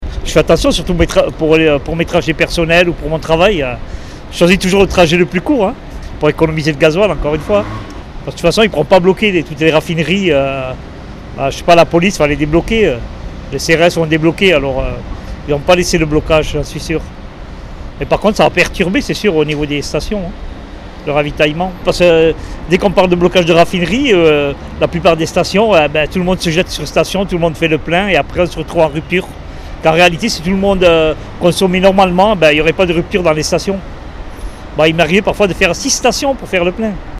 Pourtant dans les stations, les automobilistes attendent de voir l'évolution de la situation.